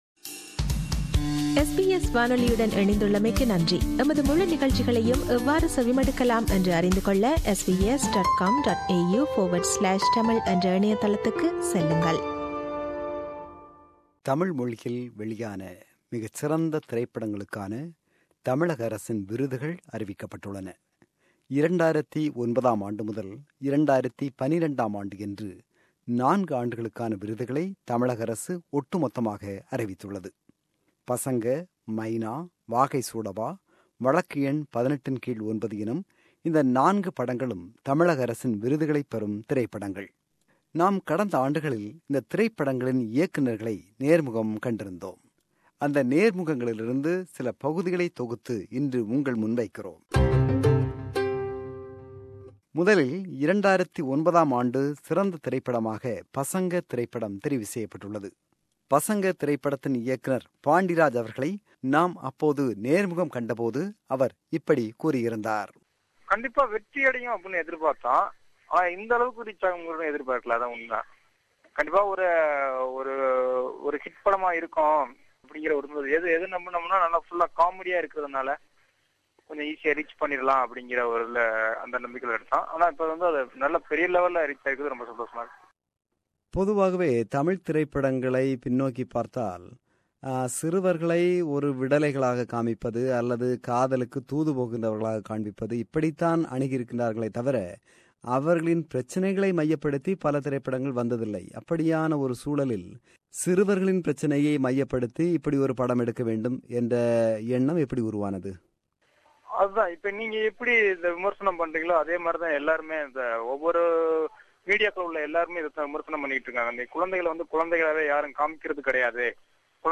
Interview with four award-winning directors
This is the compilation of interviews given by those respective film directors to SBS Tamil.